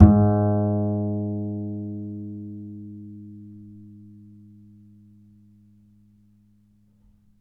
DBL BASS A.1.wav